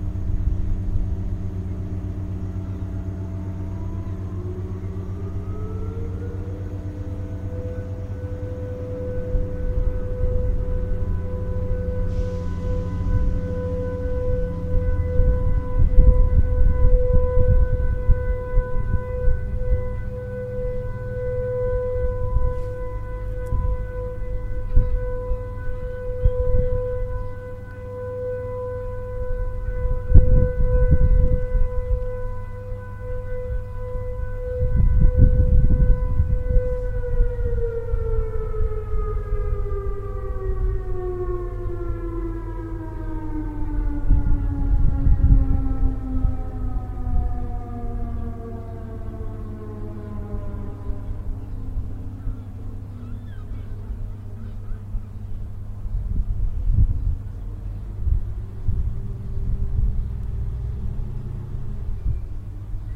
siren.ogg